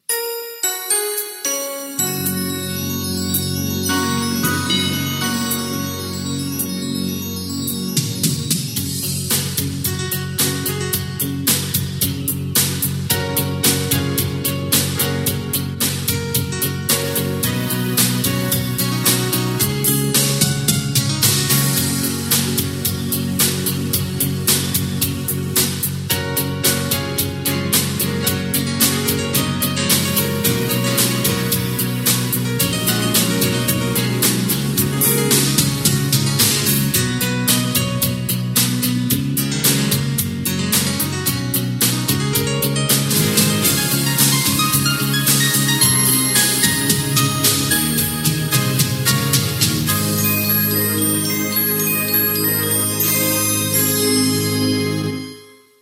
Sintonia corporativa